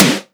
edm-snare-47.wav